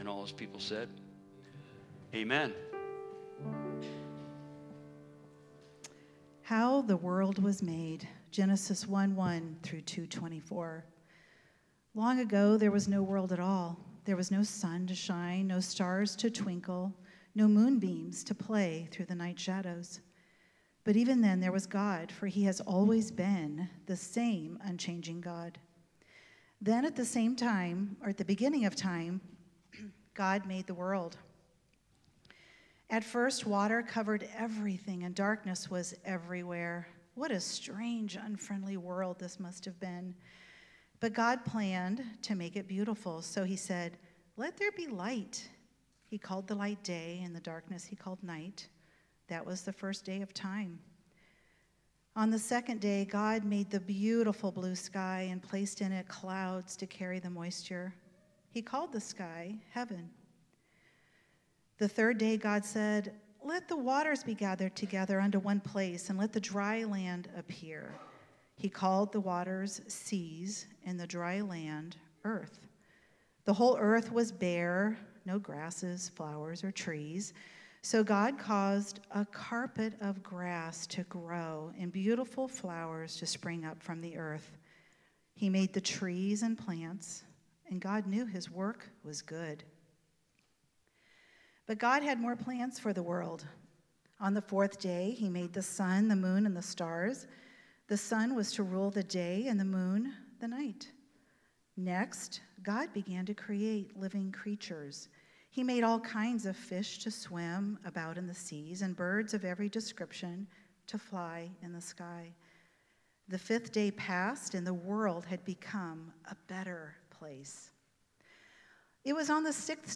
4.27.25 Sermon.m4a